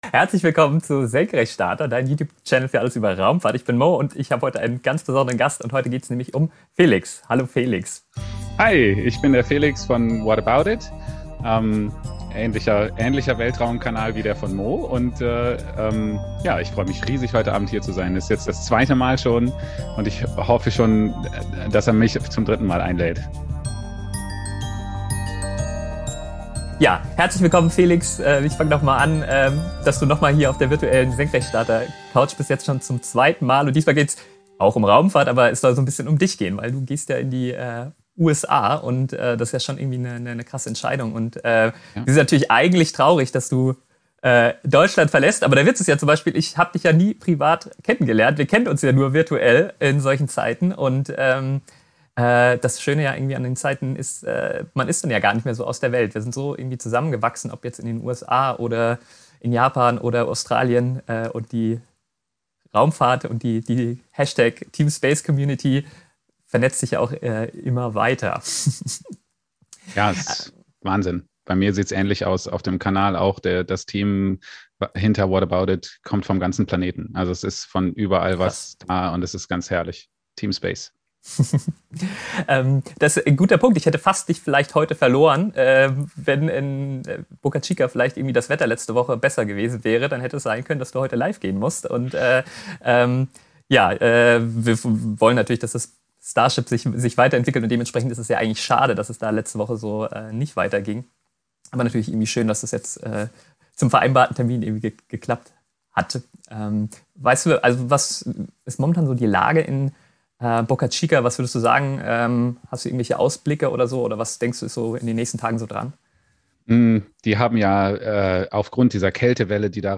Ich hab den Livestream geschnitten und mit Untertiteln versehen (auf YouTube hier zum Nachhören als Podcast) Du hast es bestimmt schon mitbekommen